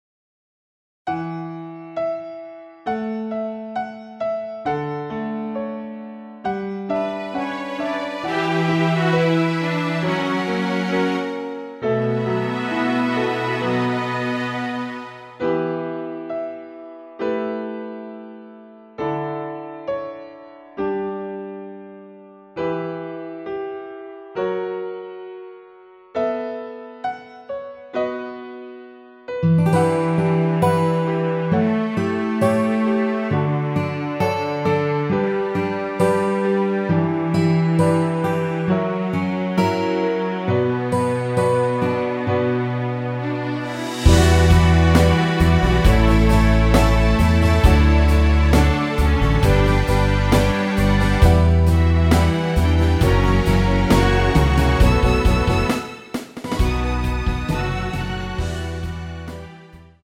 원키에서(-6)내린 MR입니다.
Bm
앞부분30초, 뒷부분30초씩 편집해서 올려 드리고 있습니다.
중간에 음이 끈어지고 다시 나오는 이유는